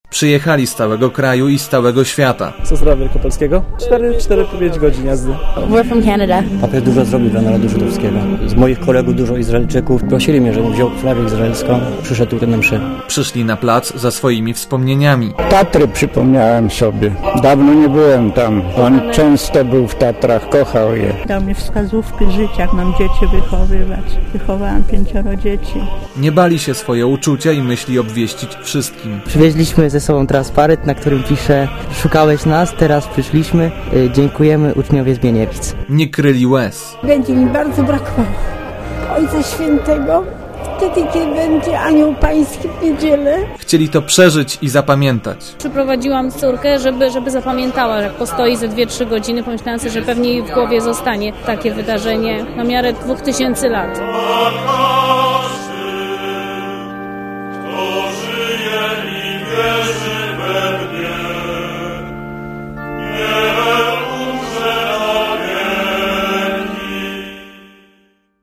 msza.mp3